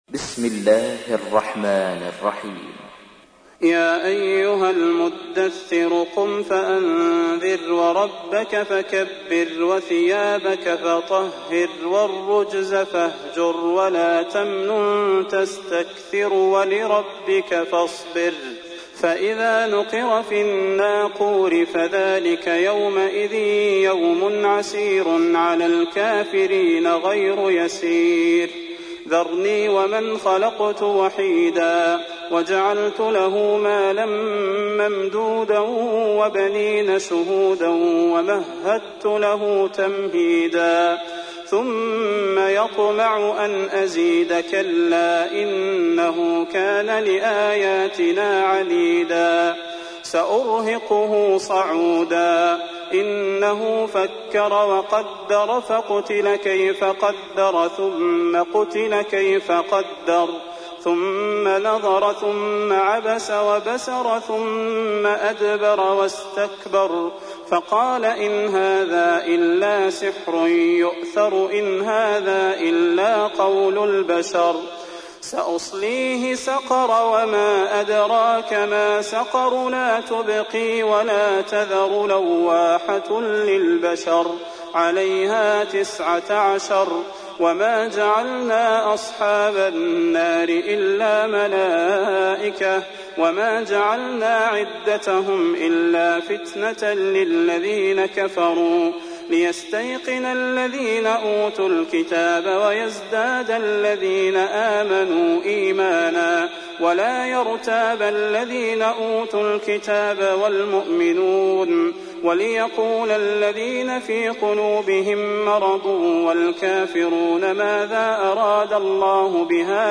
تحميل : 74. سورة المدثر / القارئ صلاح البدير / القرآن الكريم / موقع يا حسين